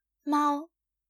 1-1.「猫 (māo)」の読み方
マオ
中国語の「猫」の発音はカタカナで表すと「マオ」。ピンインは「māo」で、声調は第一声です。